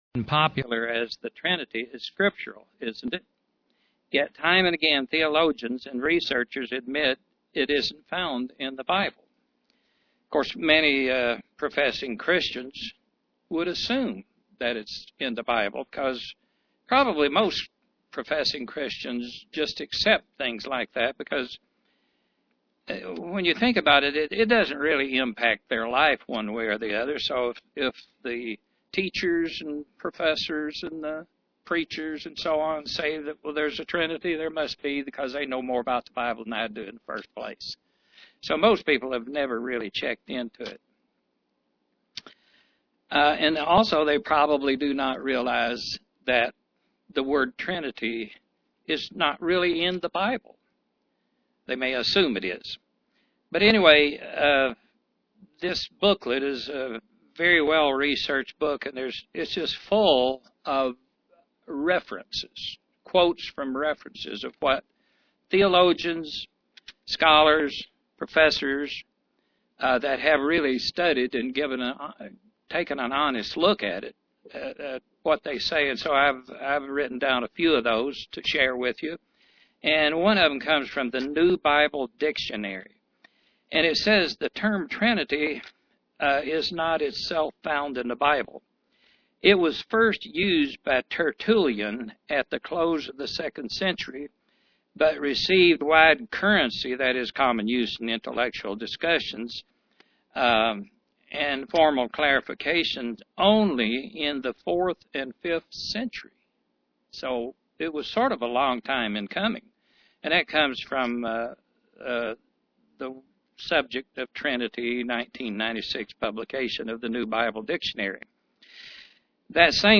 Print Truth about the trinity doctrine UCG Sermon Studying the bible?